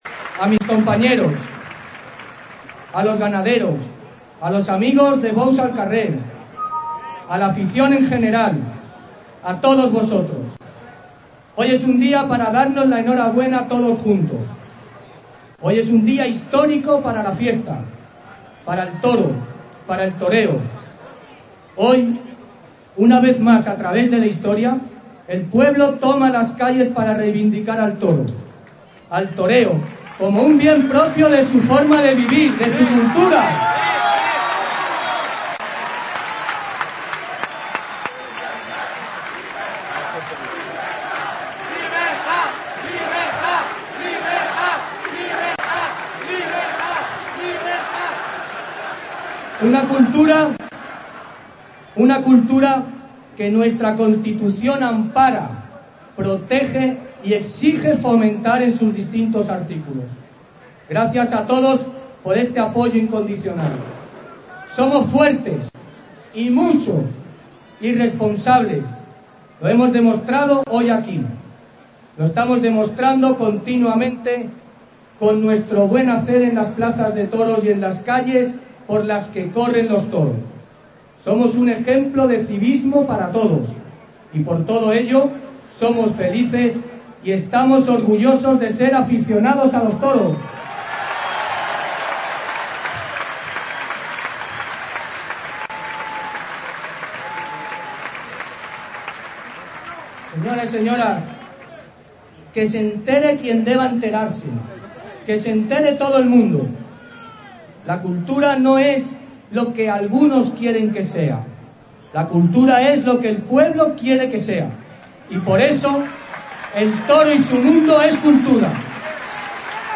#13MValencia | Lectura final del manifiesto a cargo de Enrique Ponce